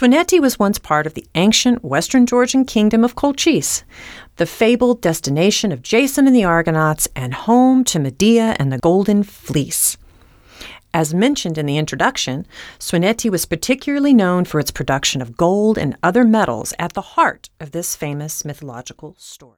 Audio Trail Guide